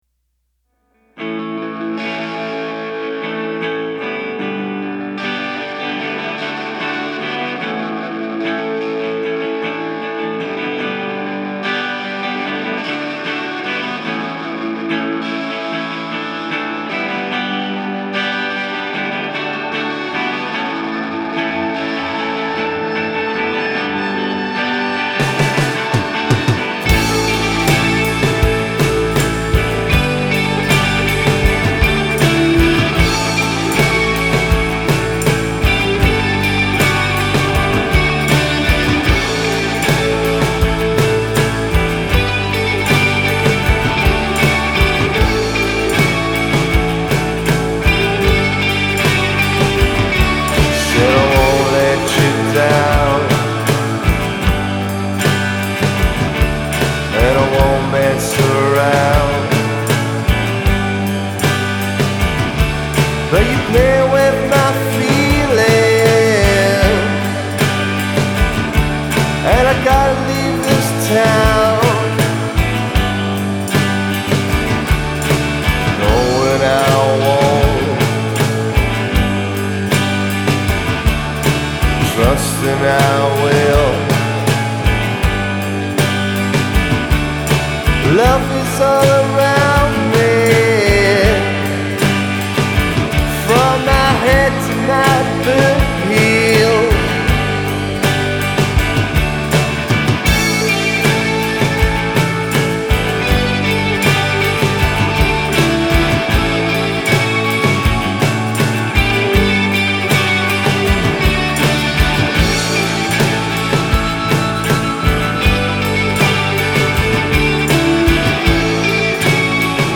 Genre: Indie/Psychedelic-Rock